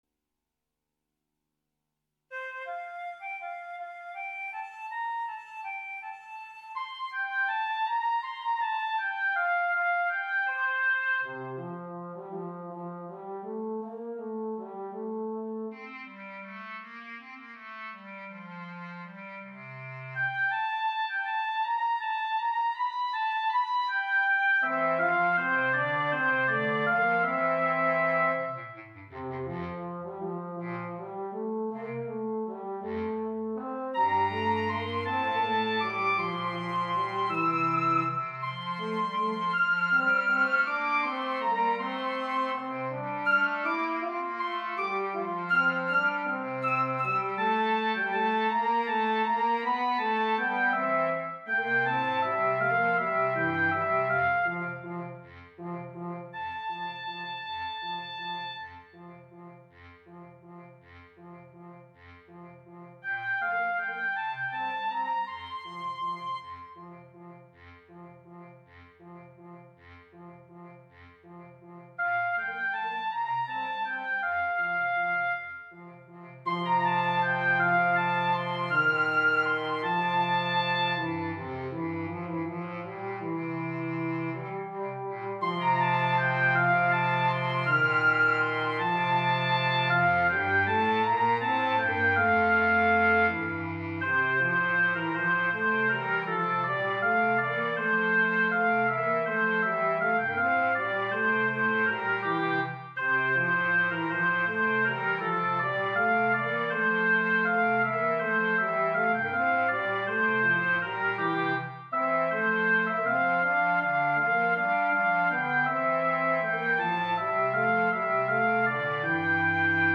minus Instrument 1